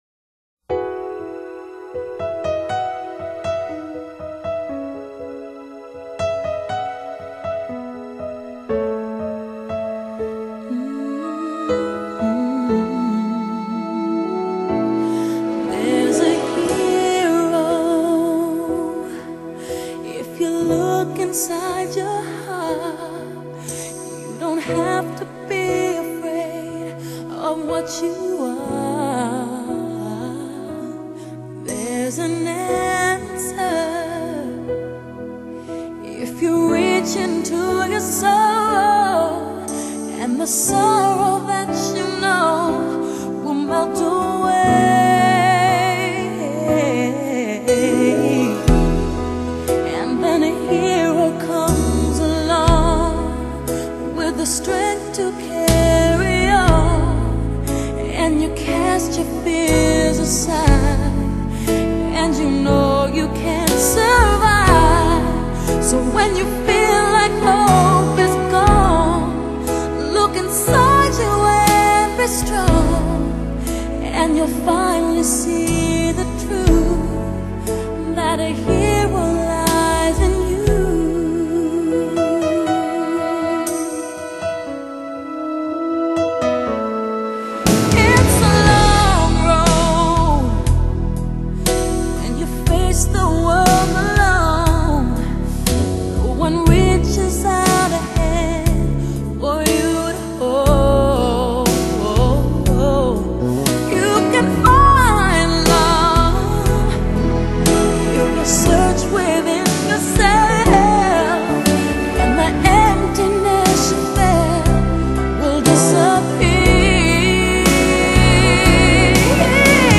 天籟般的嗓音是最適合表達深情的情歌
聆聽沒有任何世俗渲染的音樂配上清泉般透徹的歌聲